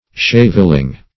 Search Result for " shaveling" : The Collaborative International Dictionary of English v.0.48: Shaveling \Shave"ling\, n. A man shaved; hence, a monk, or other religious; -- used in contempt.